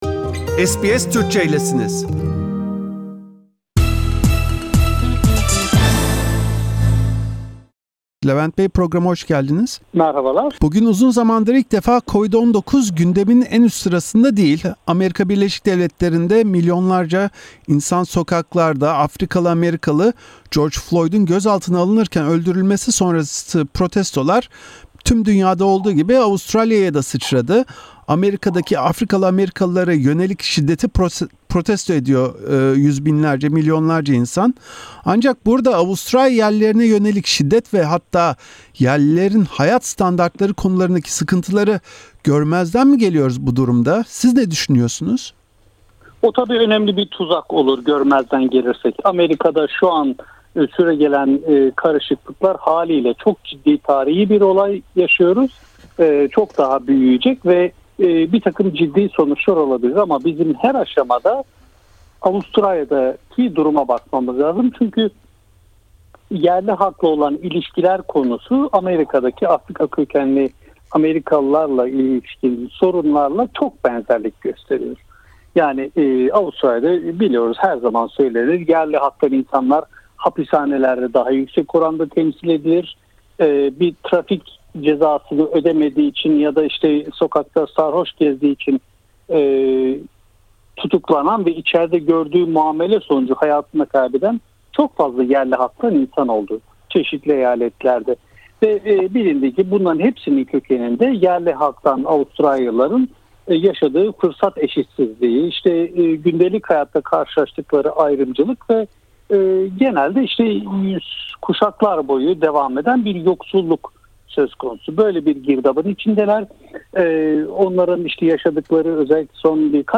SBS Türkçe’ye verdiği haftalık röportajında